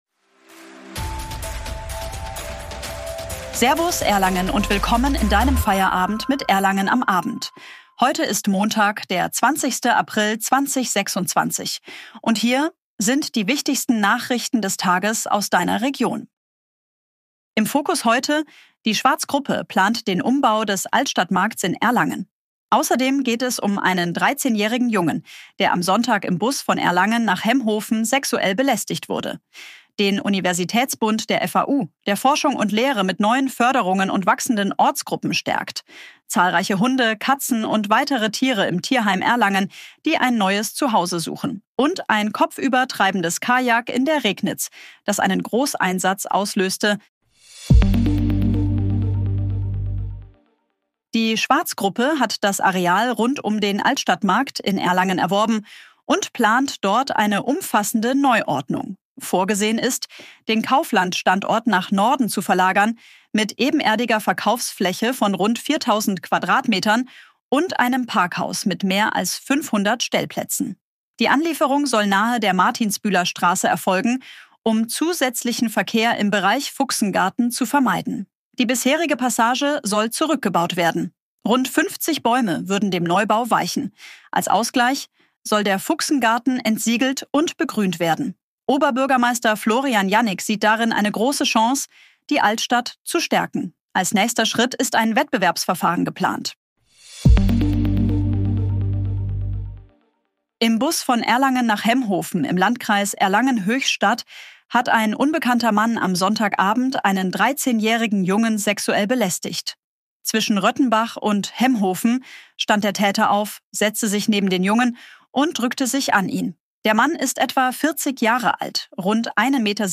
Willkommen zu deinem täglichen News-Update